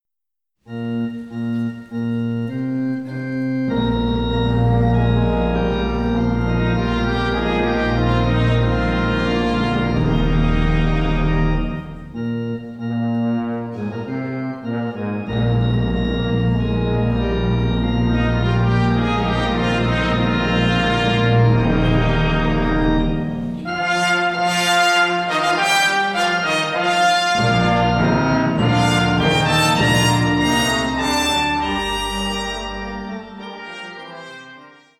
• kurzweilige Zusammenstellung verschiedener Live-Aufnahmen
Gemeinde, Chor, Kinderchor, Orgel, Orchester